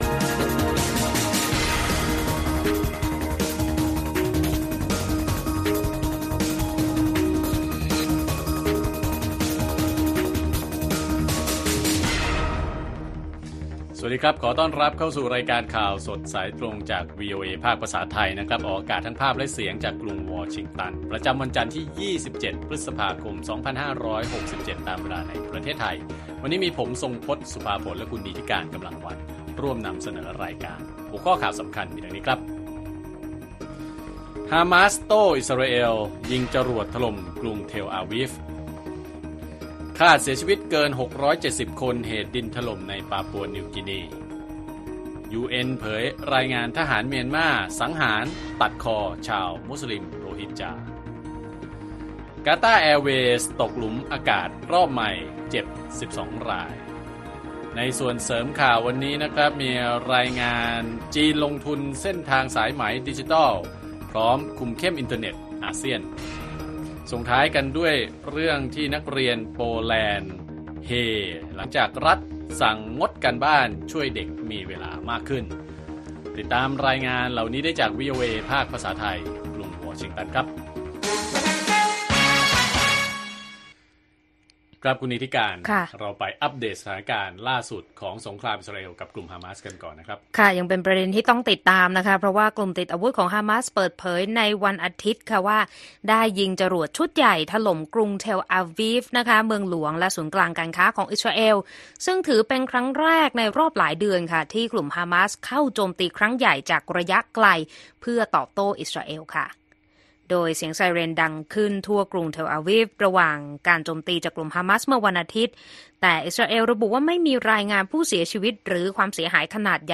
ข่าวสดสายตรงจากวีโอเอไทย วันจันทร์ ที่ 27 พ.ค. 2567